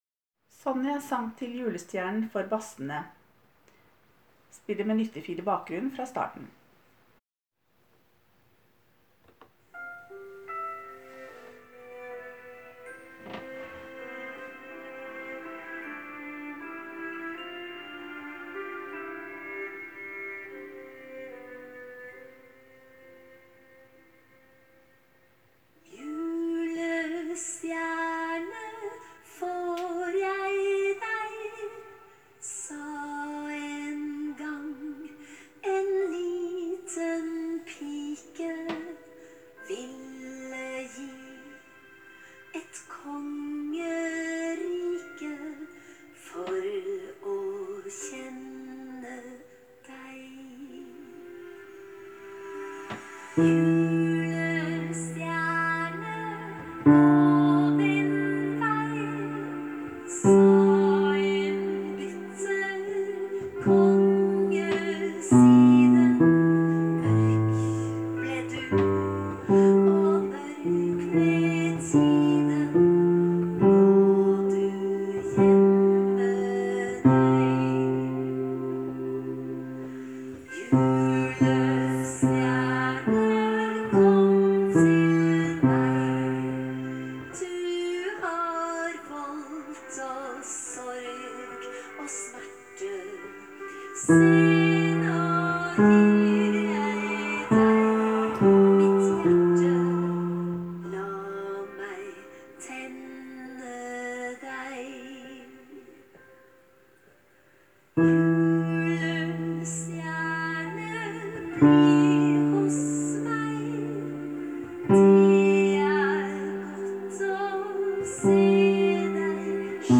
Jul 2017 Bass (begge konserter)
Sonjas sang til julestjernen med lyttefil i bakgrunnen:
Sonjas-sang-til-julestjernen-Basser-Lyttefil-i-bak.m4a